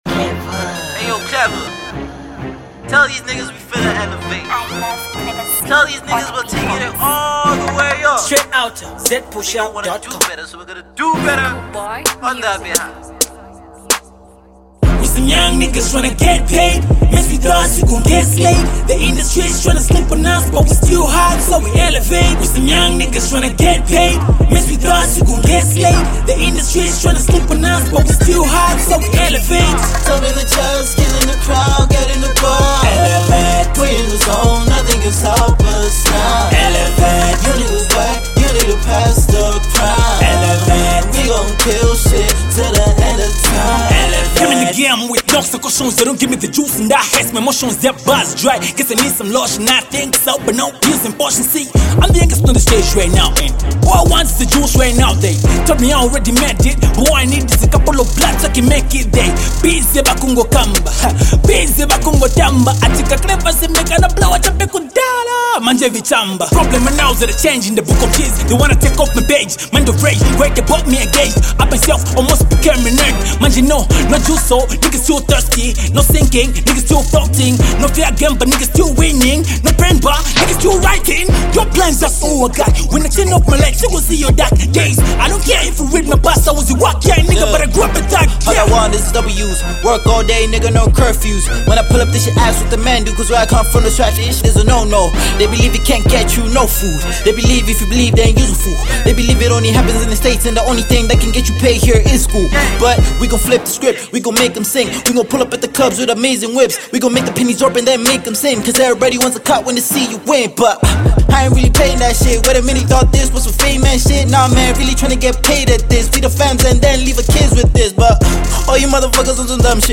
Get the RnB joint and stay lovely.